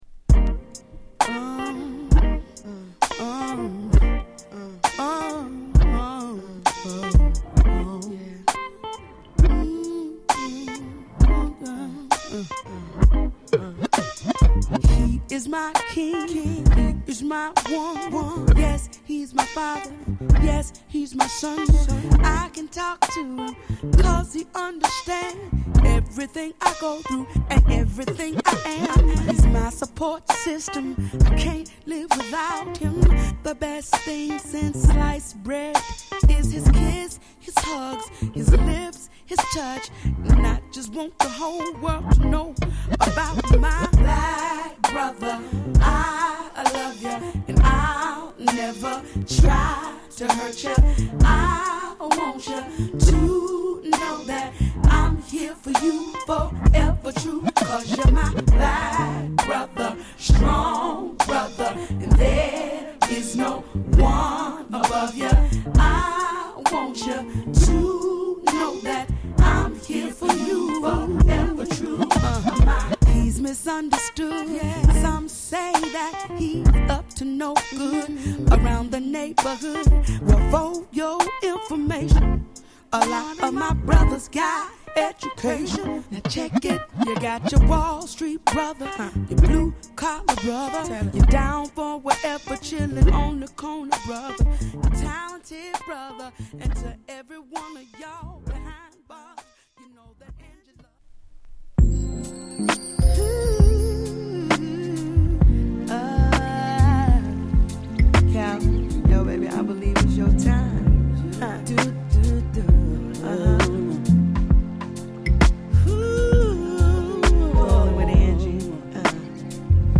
より成熟したオーガニック・ソウルを聴かせる完成度高い1枚！